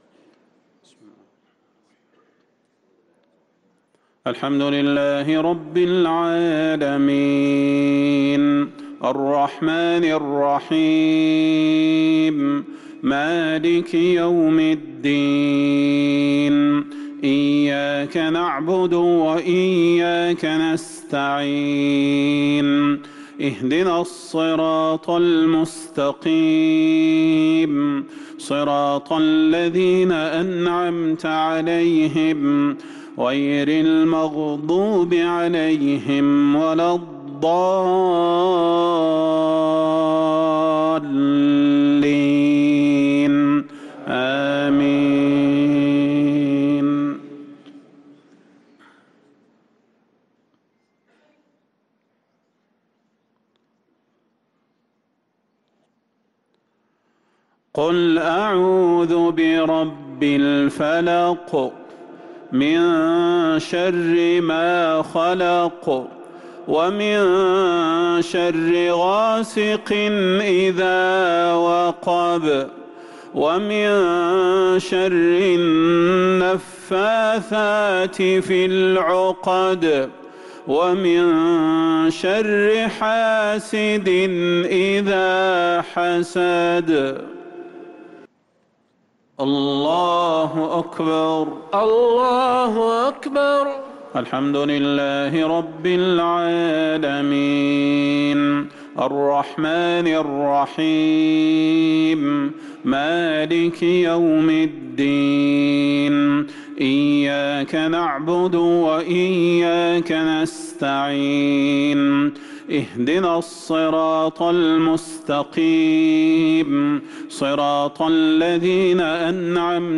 صلاة المغرب للقارئ صلاح البدير 2 جمادي الآخر 1444 هـ
تِلَاوَات الْحَرَمَيْن .